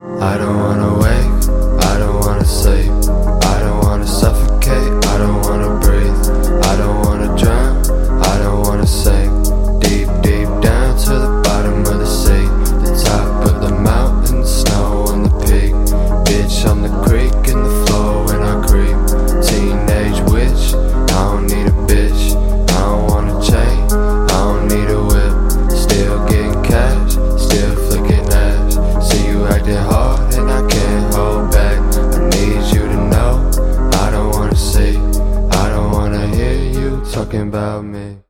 • Качество: 192, Stereo
спокойные
медленные
relax